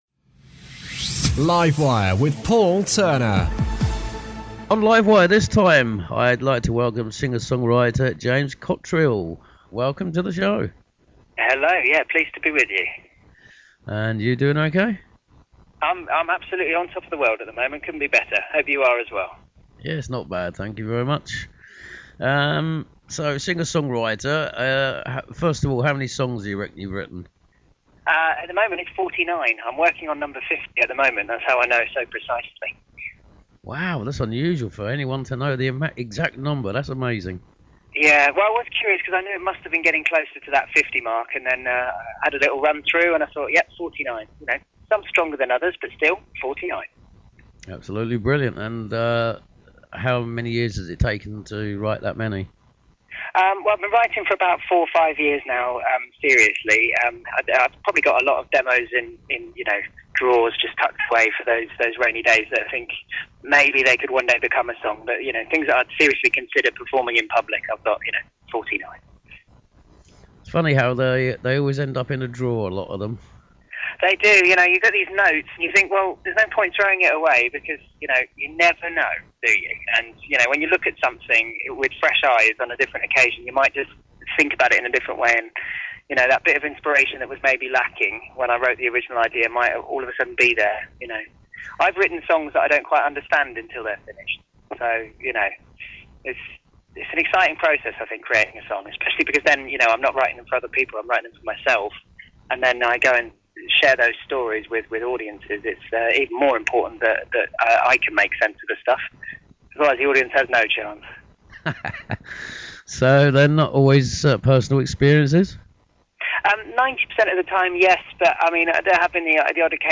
talks to singer/songwriter James Cottriall. He became famous throughout Austria with the success of his first single, "Unbreakable", which spent twenty weeks in the Austrian top 40 charts in summer 2010.